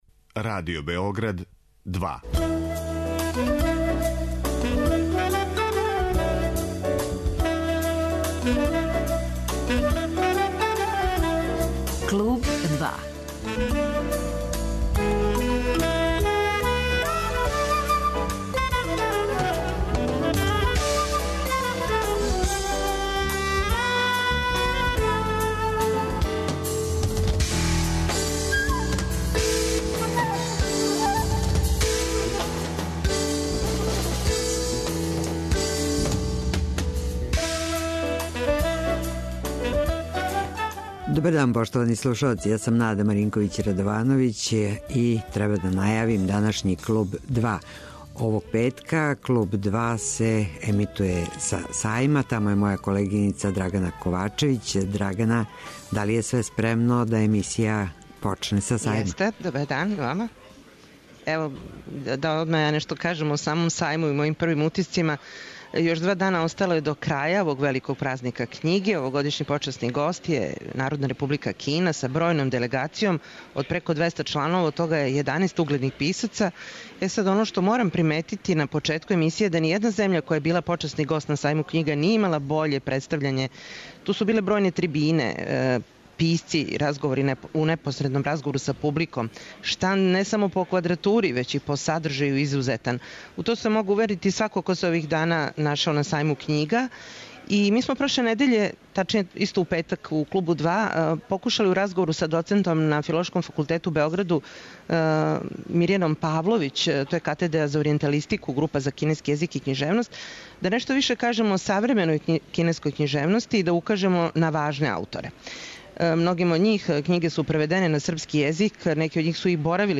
'Клуб 2' емитујемо са Сајма књига